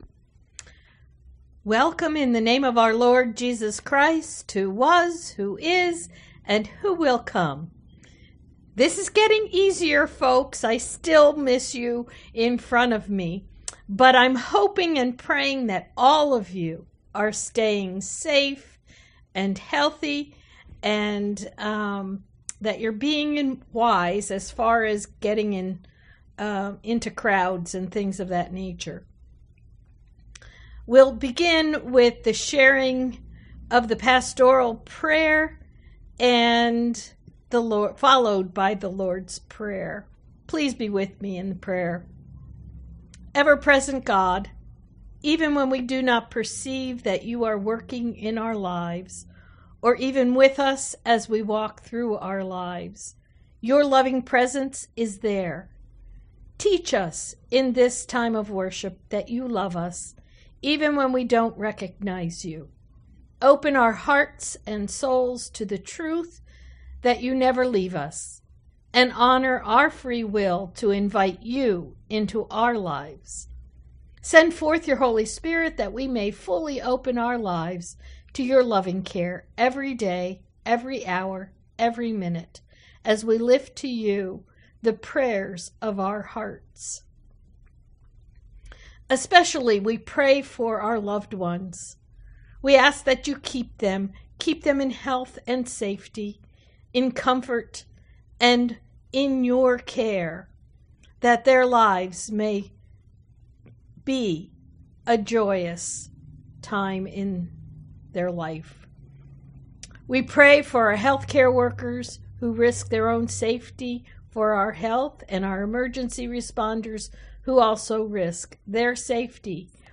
Prelude: "Rigaudon
Organist